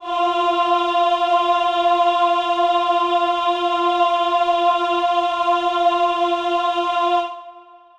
Choir Piano (Wav)
F4.wav